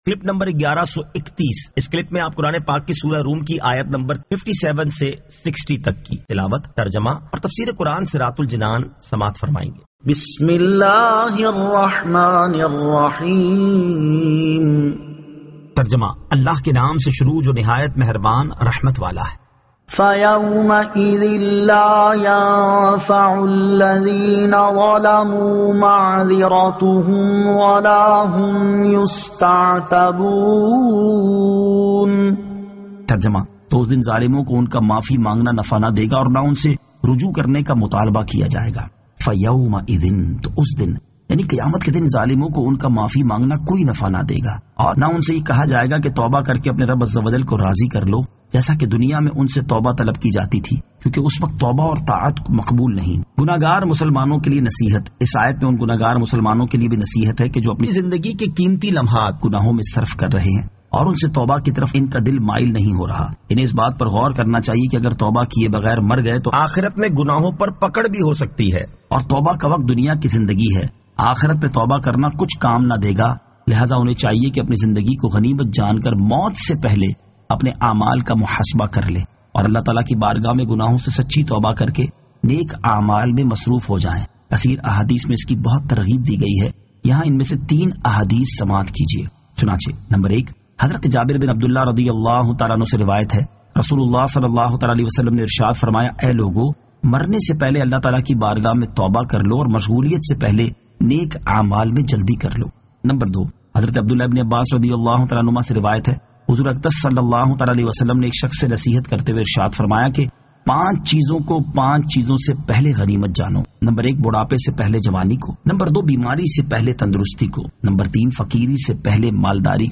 Surah Ar-Rum 57 To 60 Tilawat , Tarjama , Tafseer